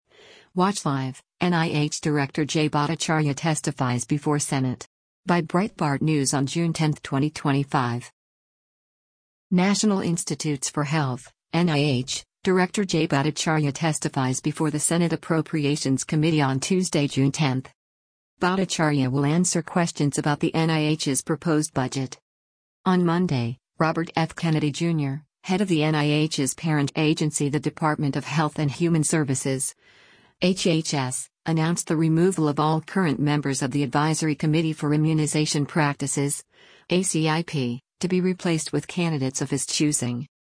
National Institutes for Health (NIH) Director Jay Bhattacharya testifies before the Senate Appropriations Committee on Tuesday, June 10.